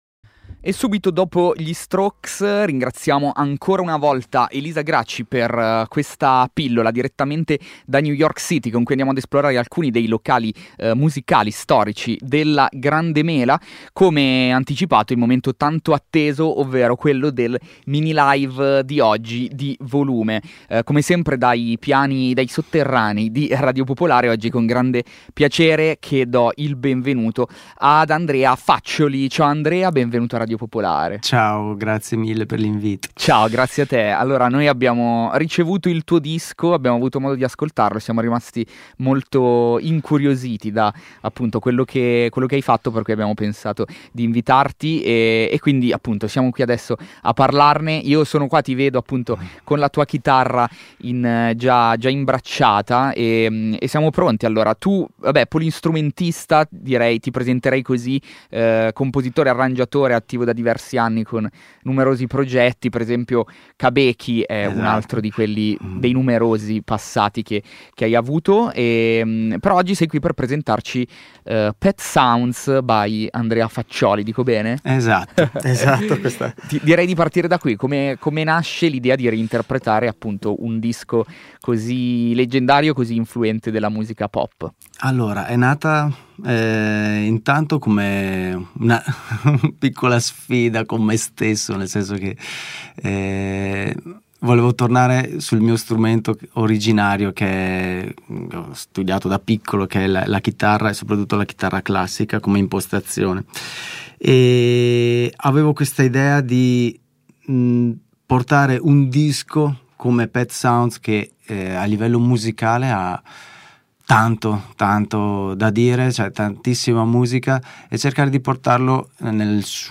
con una versione per chitarra acustica sola
una nuova prospettiva intima e contemplativa
L'intervista